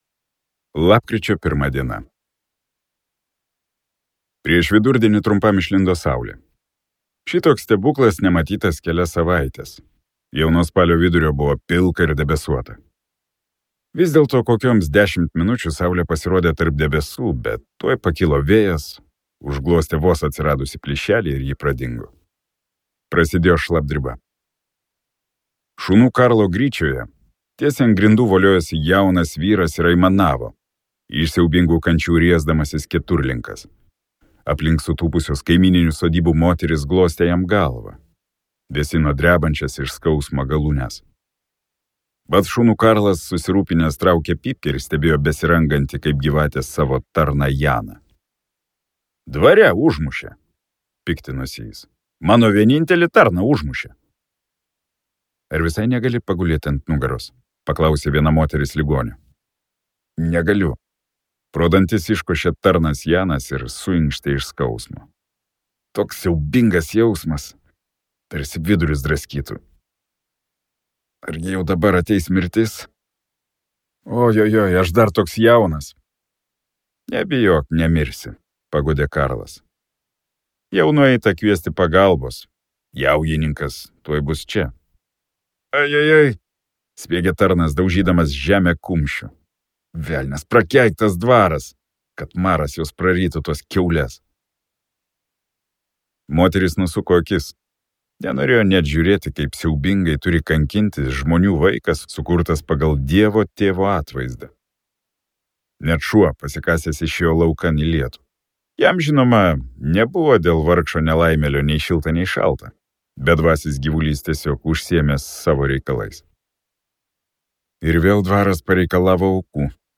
Jaujininkas | Audioknygos | baltos lankos